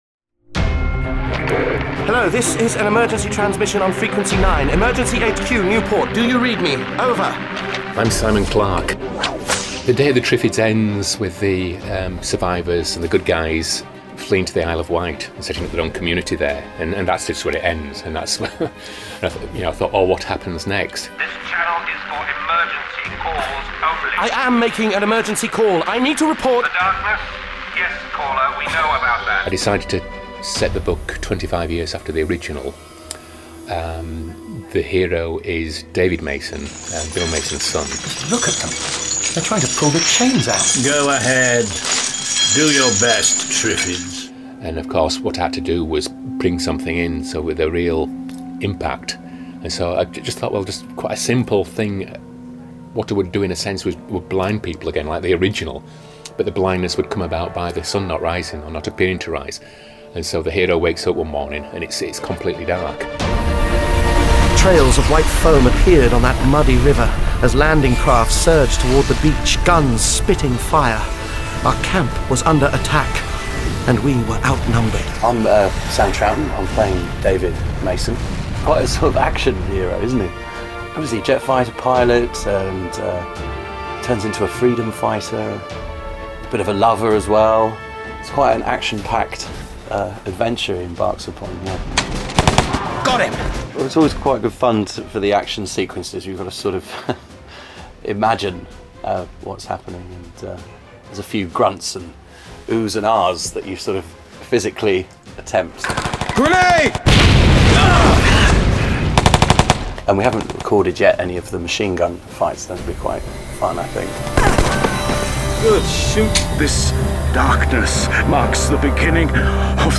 An hour of cast and crew behind-the-scenes interviews, this extra content will only be available to those who purchase (or have already purchased) the title from Big Finish direct
the.night.of.the.triffids.-.disc.3.-.promo.mp3